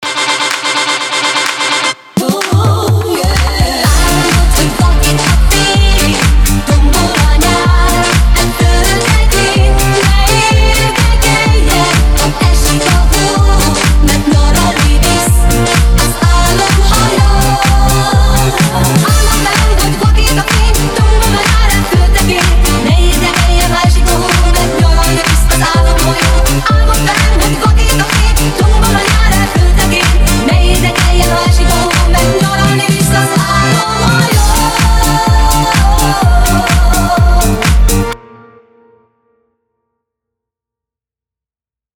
Disco/Funky remix